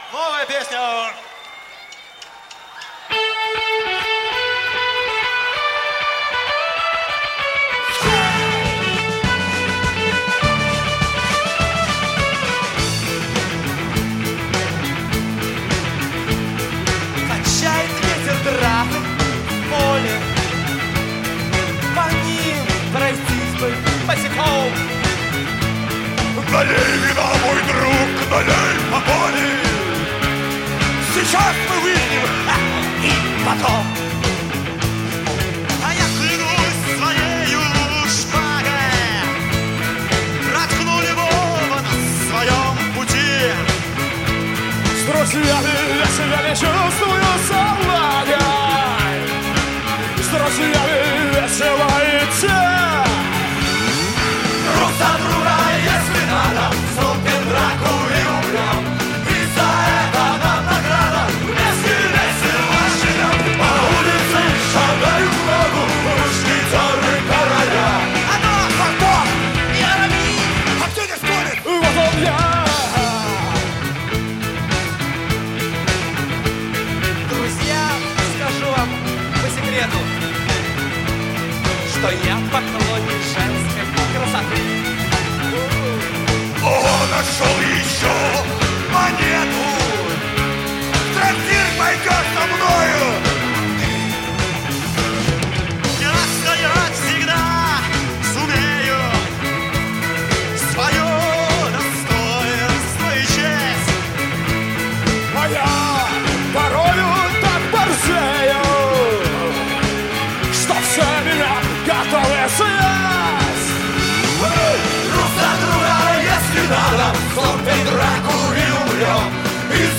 Метал
Жанр: Метал / Рок